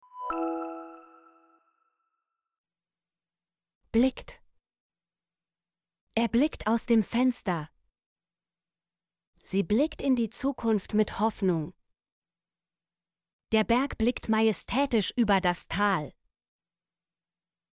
blickt - Example Sentences & Pronunciation, German Frequency List